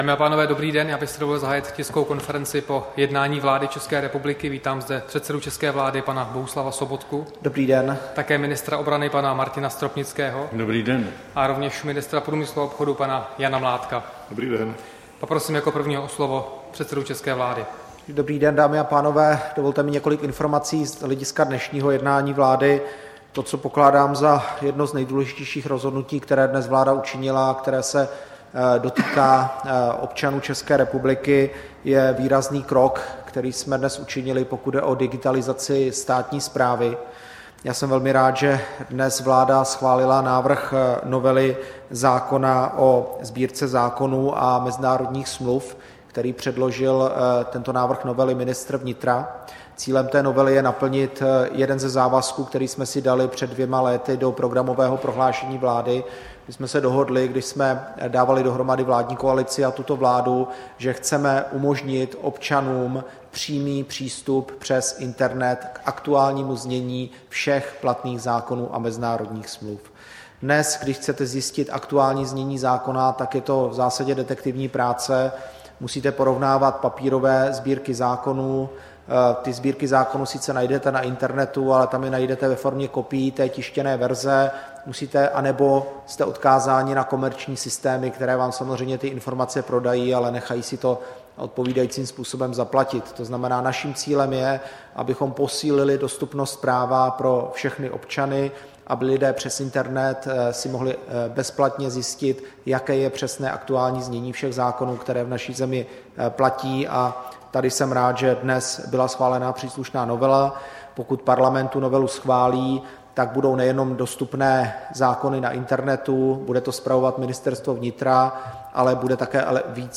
Tisková konference po jednání vlády 26. října 2015